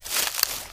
STEPS Bush, Walk 24.wav